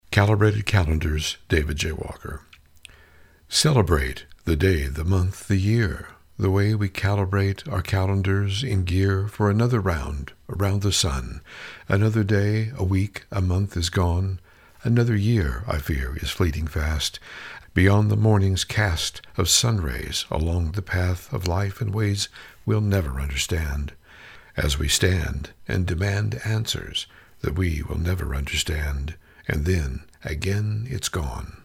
You have a great voice or spoken word and i agree with your sentiments..